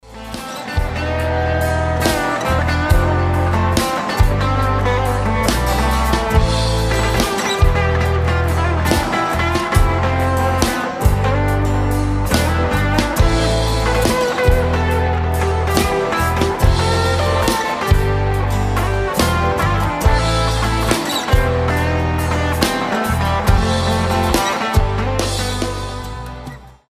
гитара
без слов
легкий рок
турецкие